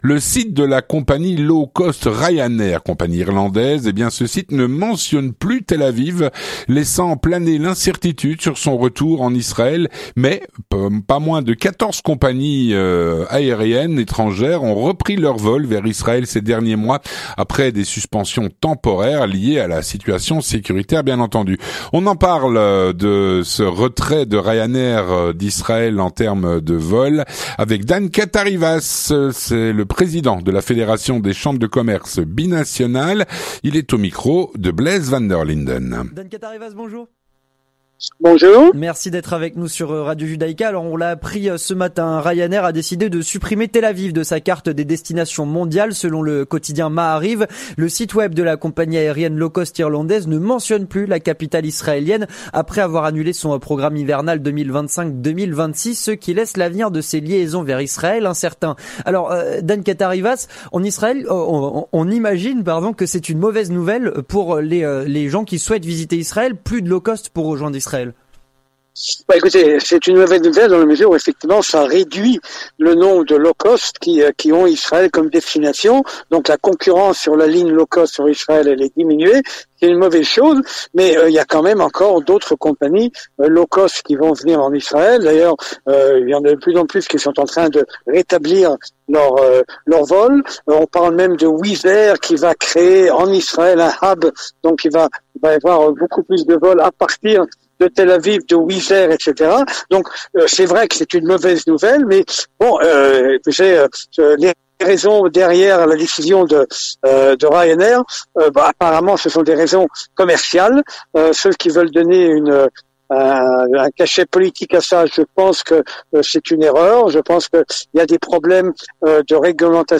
L'entretien du 18H - Le site de Ryanair ne mentionne plus Tel-Aviv comme destination.